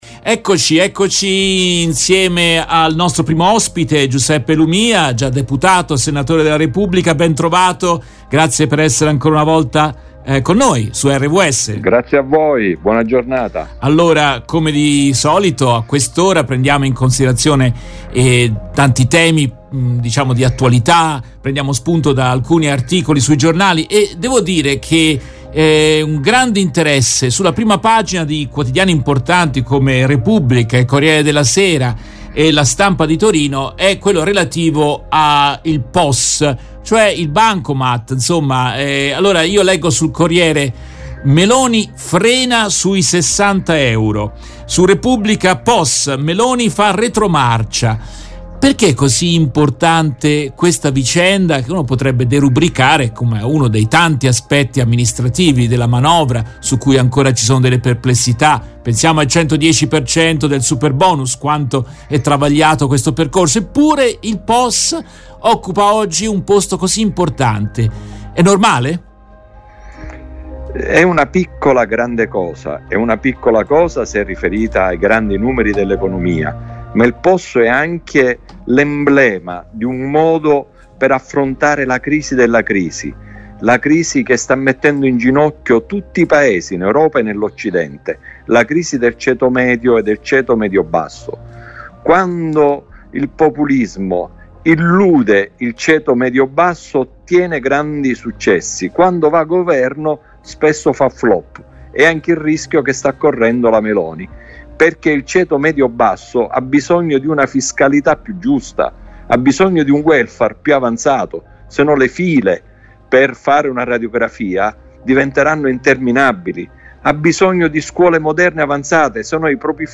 In questa trasmissione in diretta del 05 dicembre 2022